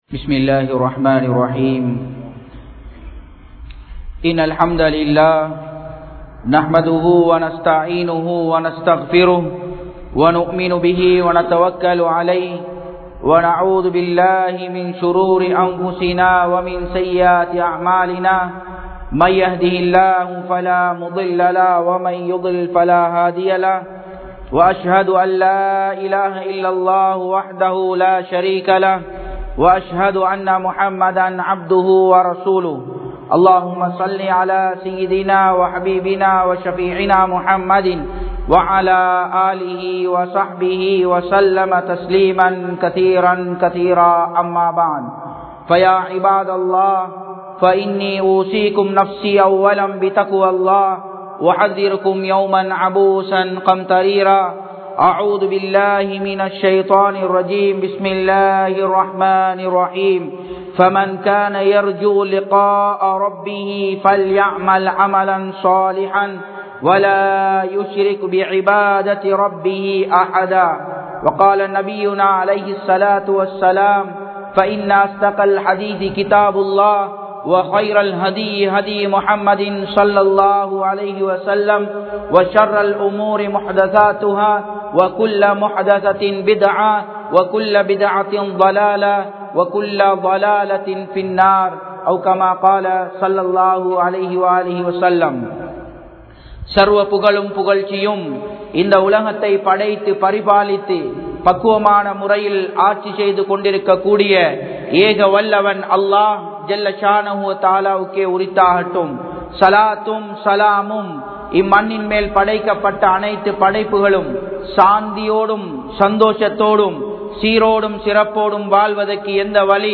Marumaiel 03Vahaiyana Manitharhalin Nilai (மறுமையில் 03வகையான மனிதர்களின் நிலை) | Audio Bayans | All Ceylon Muslim Youth Community | Addalaichenai
Japan, Nagoya Port Jumua Masjidh 2017-09-08 Tamil Download